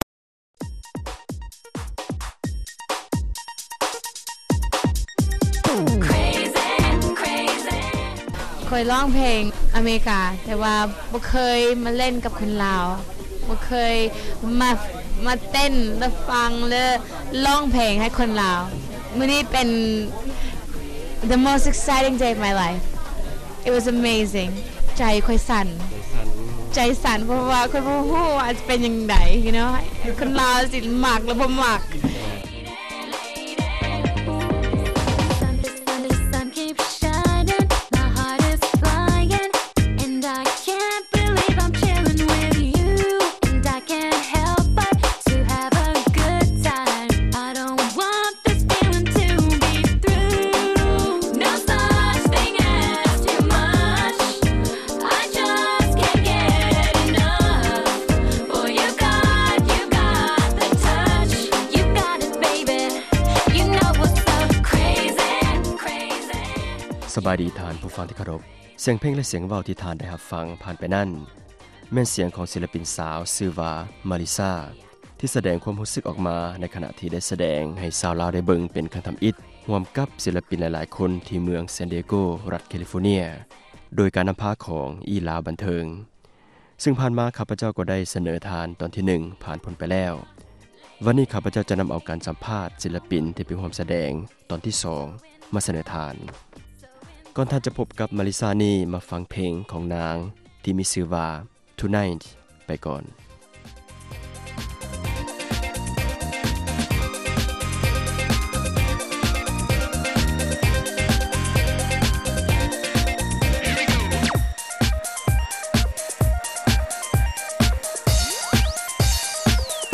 ສໍາພາດ: ສິລປິນ ຫລາຍໆຄົນ ທີ່ໄປສແດງ ຢູ່ San Diego (ຕອນທີ 2)